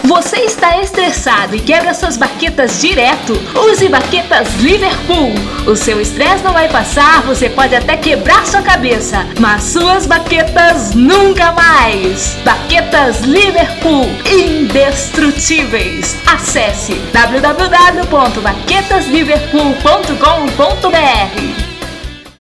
Gente, baquetas Liverpool é mais um produto fictício, mas, está aí nosso spot publicitário.
O original para o programa de rádio estilo humorístico é o primeiro, mas, a pedidos, gravei uma outra versão com background do U2!